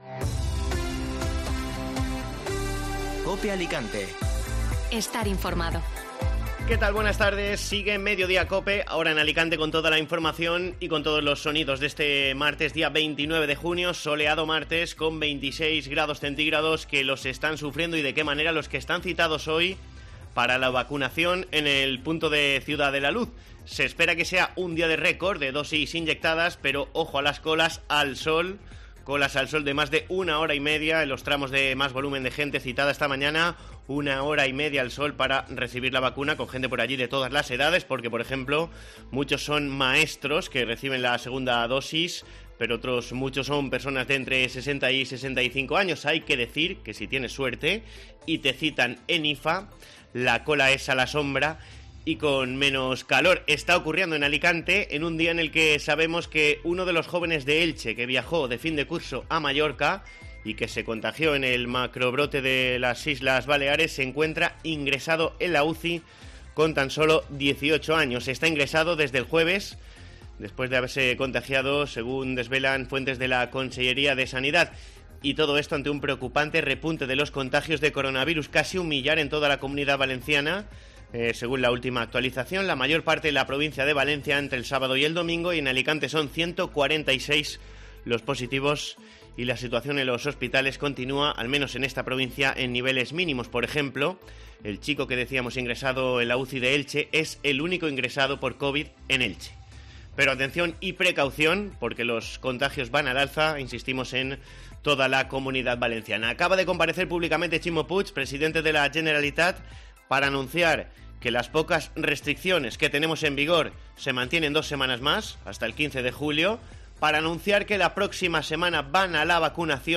Informativo Mediodía COPE (Martes 29 de junio)